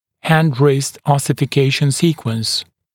[hænd-rɪst ˌɔsɪfɪ’keɪʃn ‘siːkwəns][хэнд-рист ˌосифи’кейшн ‘си:куэнс]последовательность оссификации кисти руки